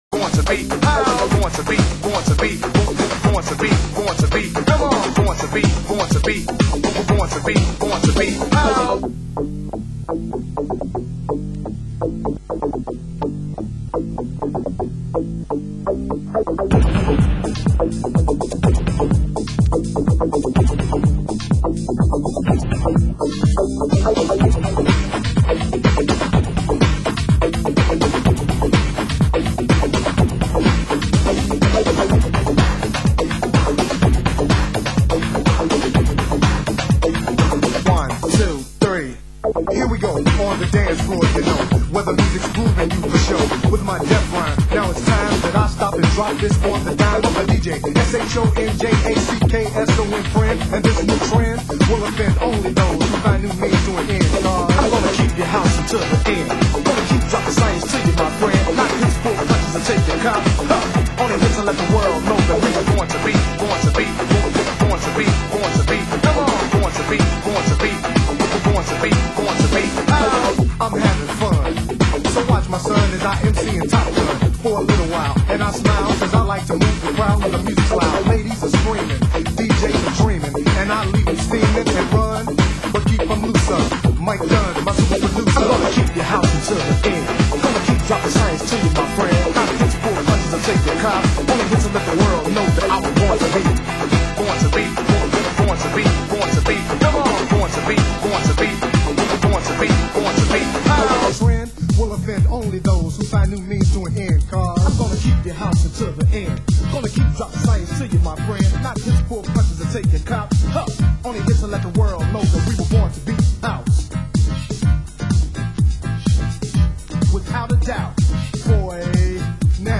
Format: Vinyl 12 Inch
Genre: US House